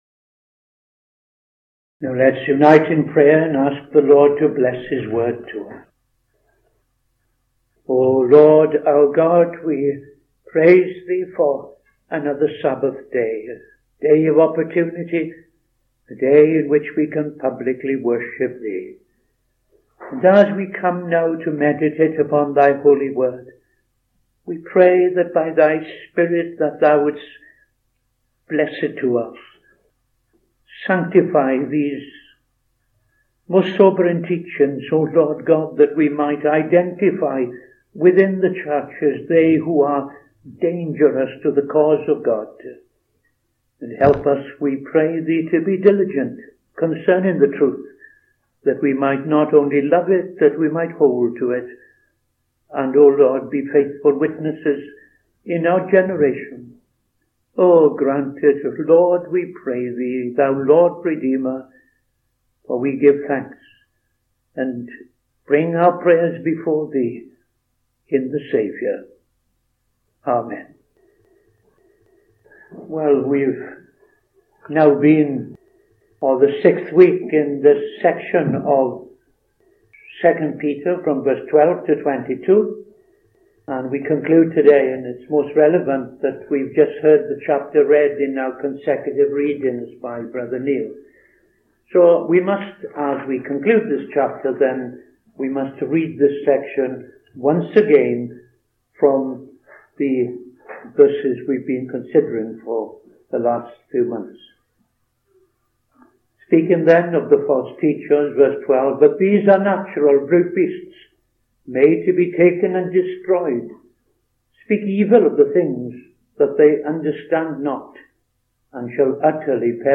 Saturday Sermon - TFCChurch
Opening Prayer and Reading II Peter 2:12-22